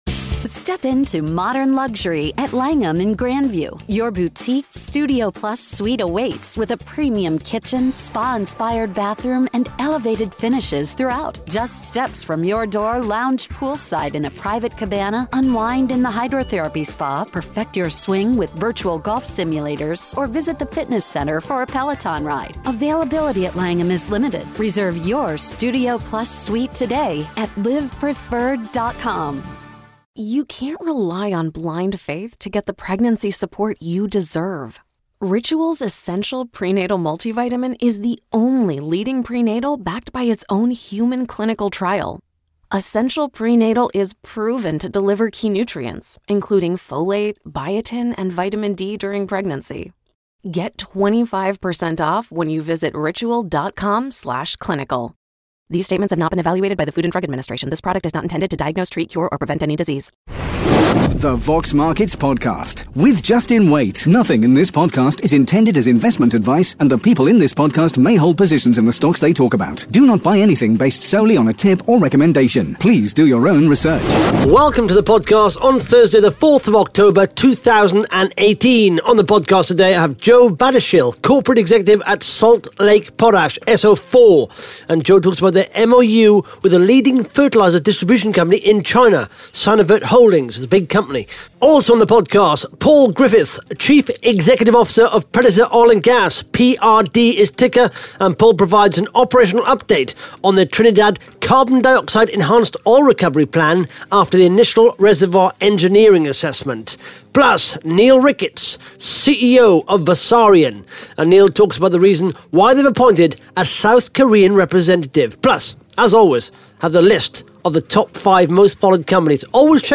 (Interview starts at 1 minutes 30 seconds)